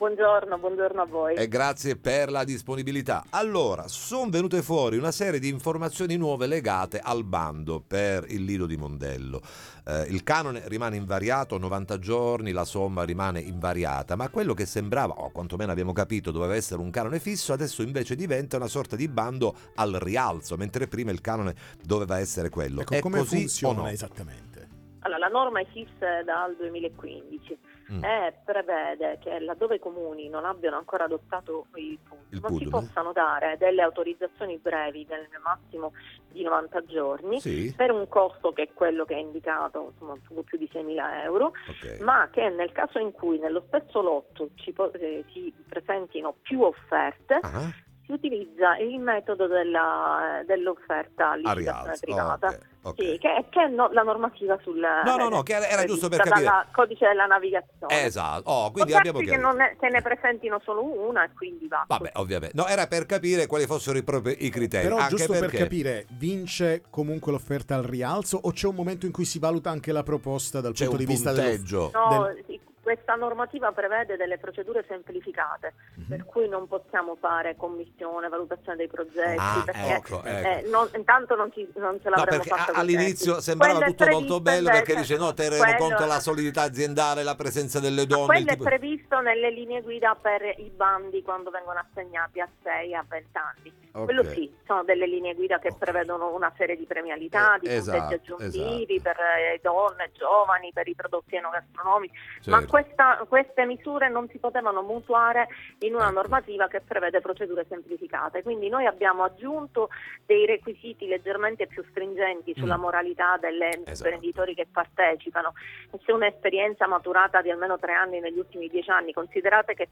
Spiaggia di Mondello, firmati decreto e bando Interviste Time Magazine 19/03/2026 12:00:00 AM / Time Magazine Condividi: Spiaggia di Mondello, firmati decreto e bando, ne parliamo con l’ass.